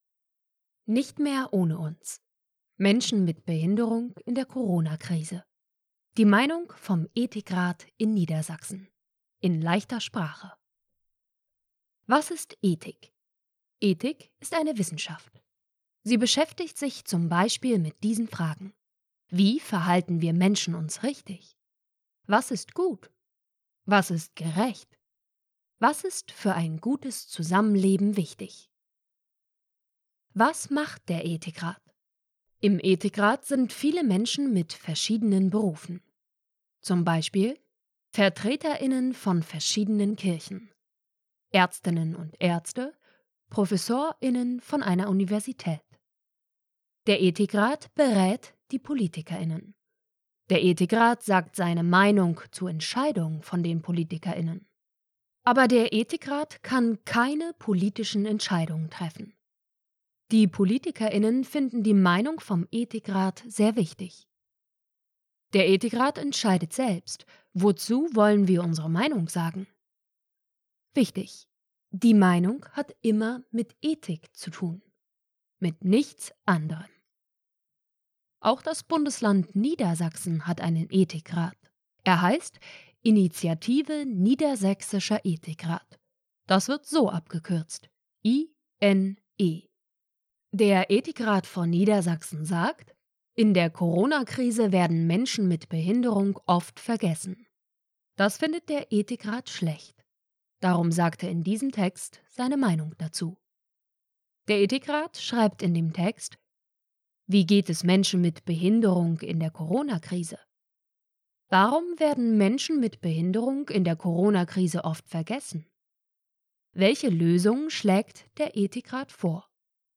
Nicht-mehr-ohne-uns-Leichte-Sprache-Audioversion.mp3